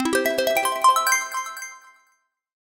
描述：An uplifting synth jingle win sound to be used in futuristic, or small casual games. Useful for when a character has completed an objective, an achievement or other pleasant events.
标签： scifi futuristic gamedeveloping celebration indiedev gamedev videogames synth achievement games game jingle win sfx electric succes videogame gaming indiegamedev
声道立体声